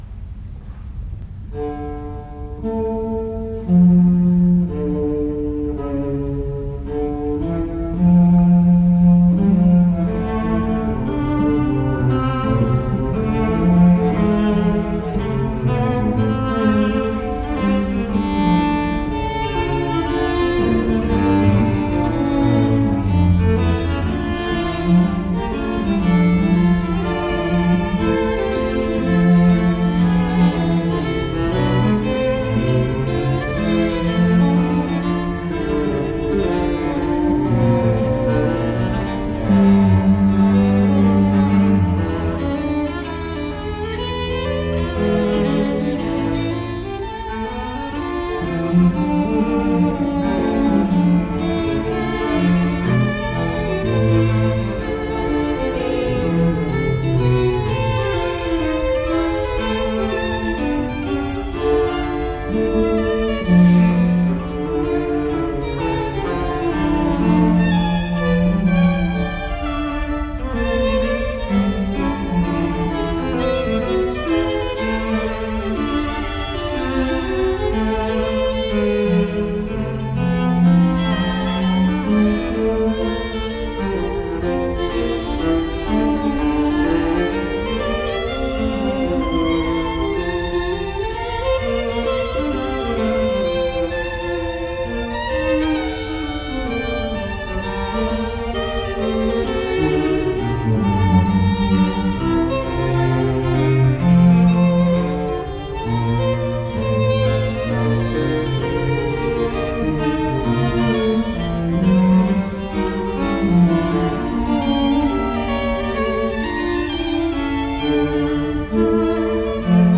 La Fuga es un procedimiento de construcción musical o forma musical que se podría definir como una composición polifónica basada en el contrapunto entre varias voces.